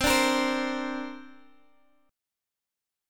Listen to C7sus2 strummed